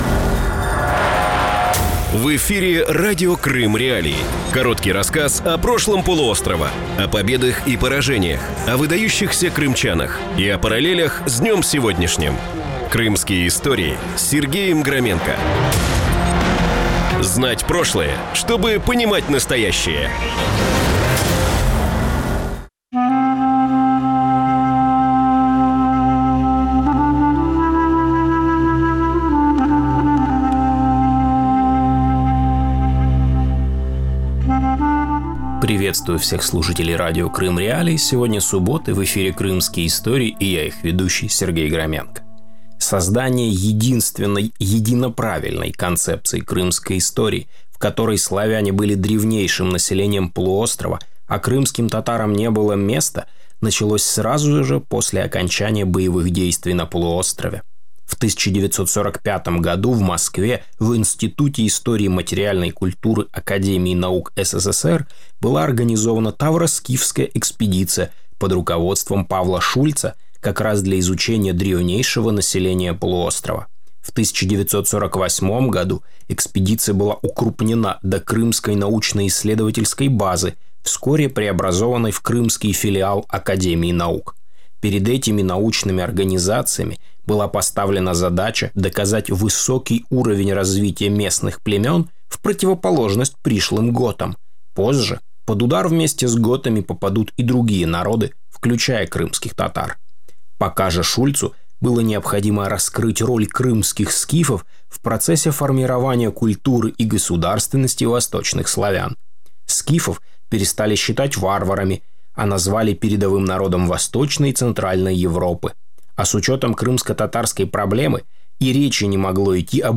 Программа звучит в эфире Радио Крым.Реалии. Это новый, особенный формат радио.